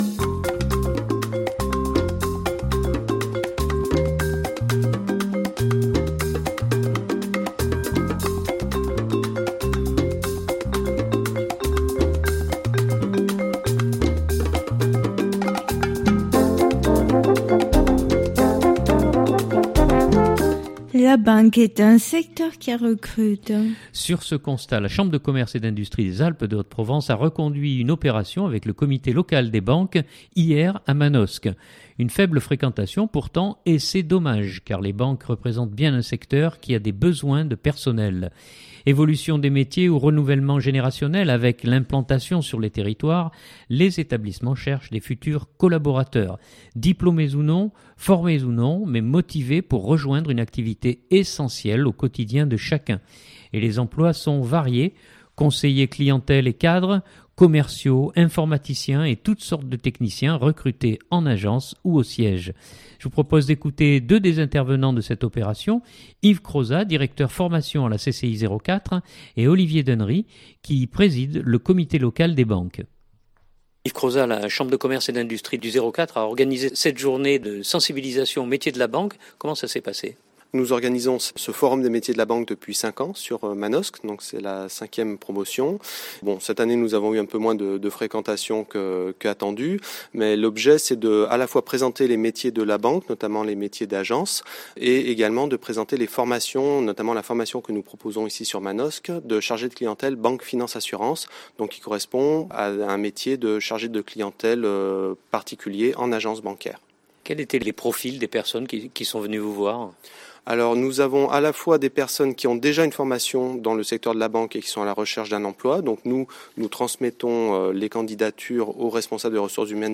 Je vous propose d’écouter deux des intervenants de cette opération